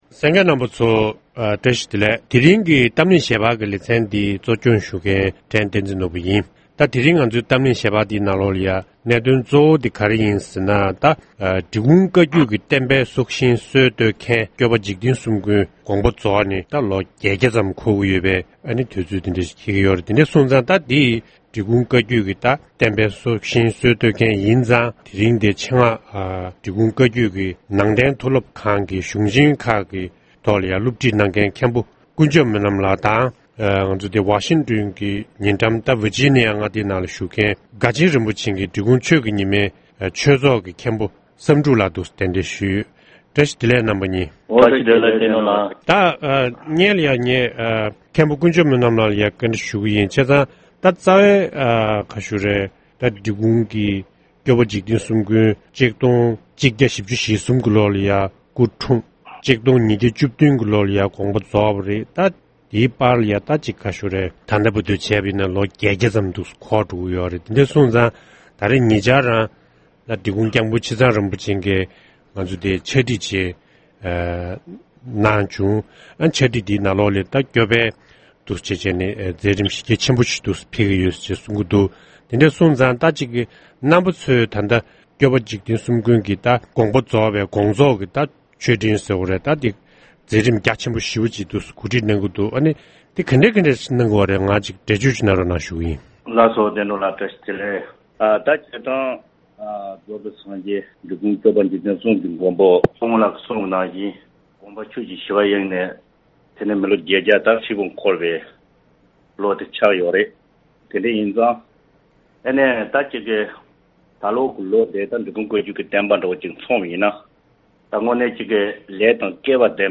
ཐེངས་འདིའི་གཏམ་གླེང་ཞལ་དཔར་གྱི་ལེ་ཚན་འདིའི་ནང་།